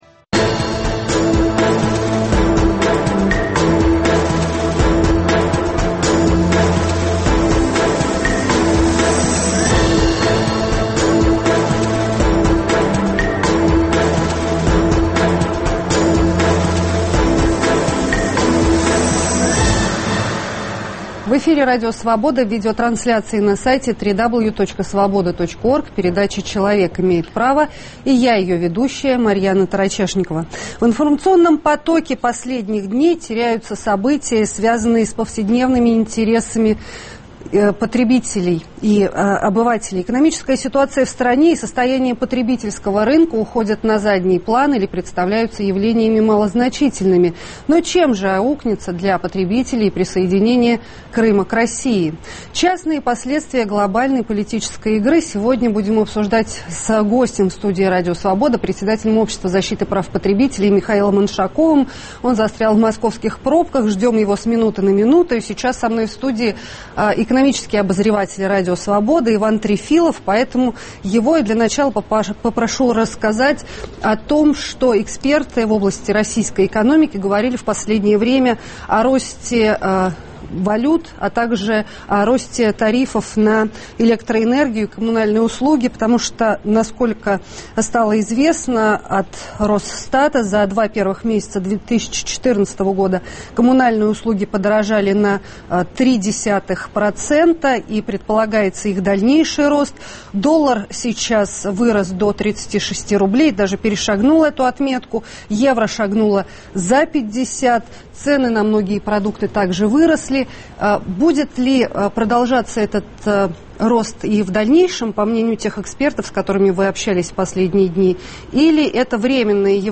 Но чем же аукнется для потребителей присоединение Крыма к России? В студии РС